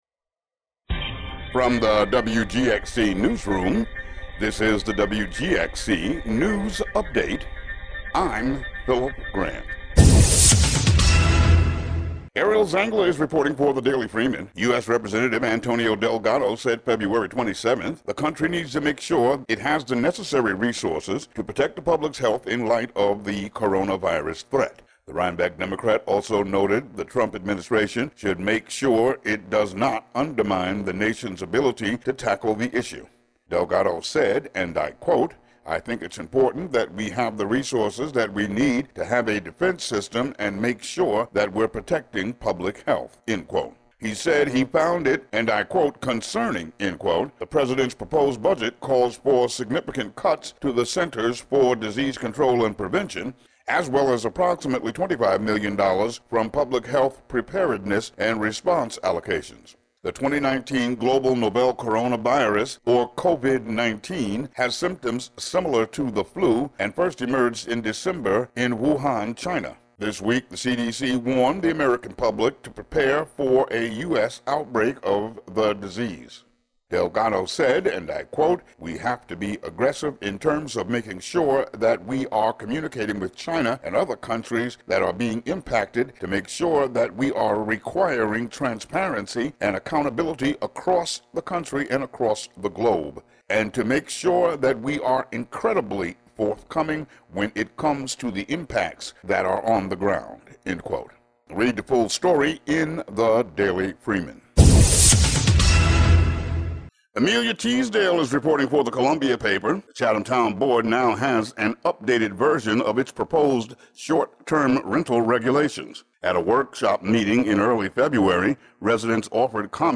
Today's local news update: